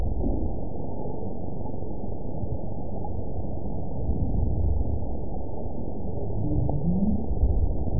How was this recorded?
event 917977 date 04/24/23 time 18:13:37 GMT (2 years ago) score 8.71 location TSS-AB04 detected by nrw target species NRW annotations +NRW Spectrogram: Frequency (kHz) vs. Time (s) audio not available .wav